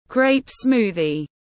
Sinh tố nho tiếng anh gọi là grape smoothie, phiên âm tiếng anh đọc là /ɡreɪp ˈsmuː.ði/
Grape smoothie /ɡreɪp ˈsmuː.ði/